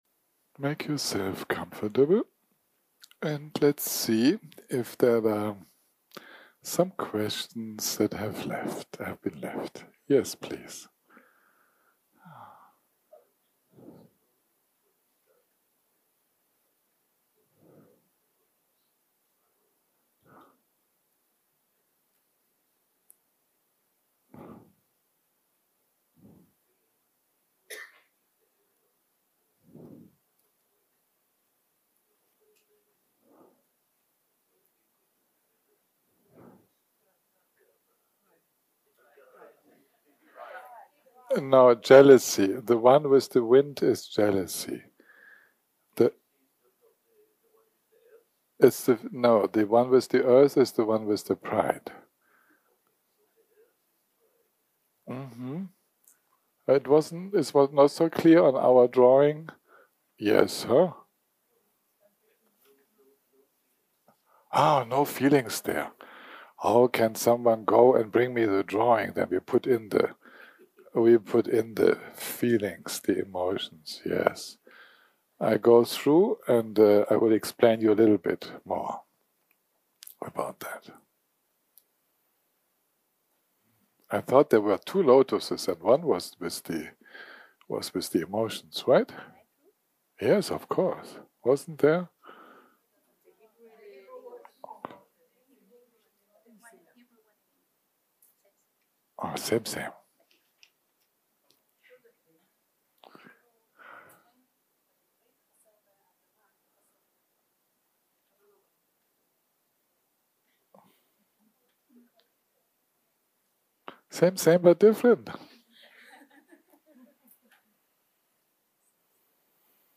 יום 8 - הקלטה 38 - אחהצ - שאלות ותשובות
Dharma type: Questions and Answers שפת ההקלטה